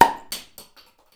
plop.wav